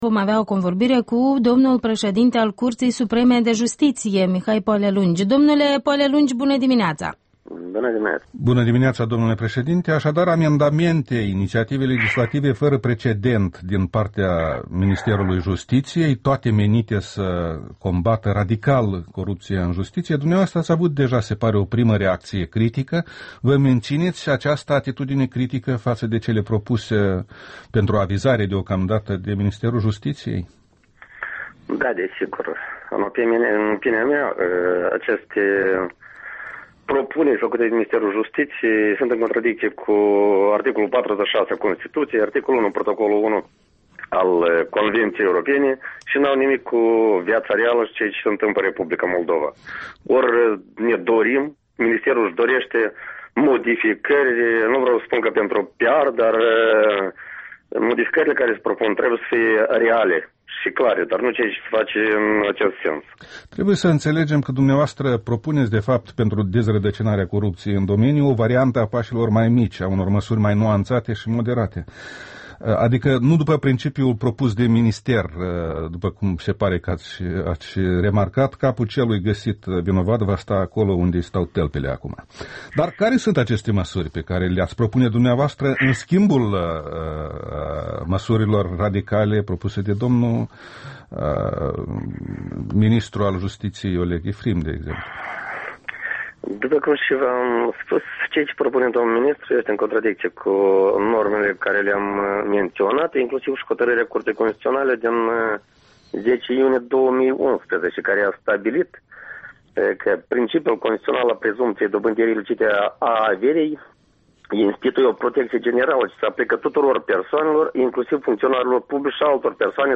Interviul dimineții la REL: cu Mihai Poalelungi, președintele CSJ